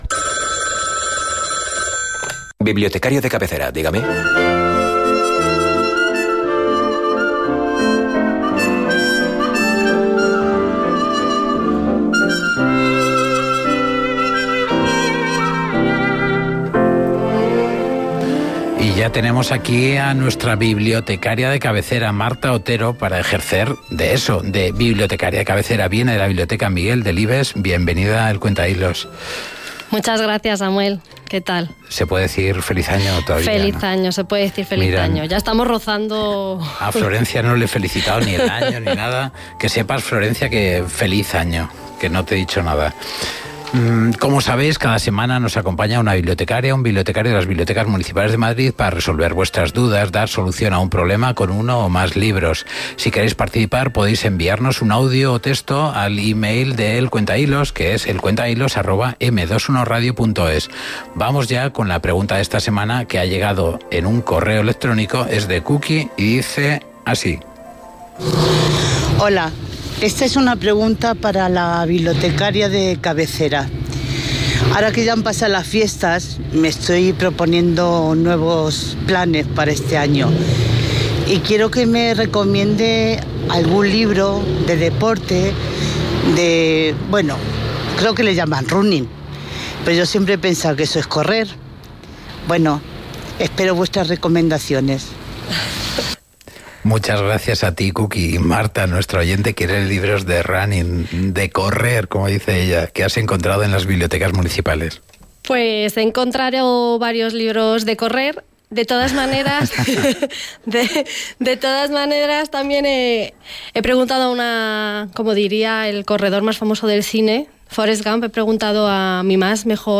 con una banda sonora muy especial.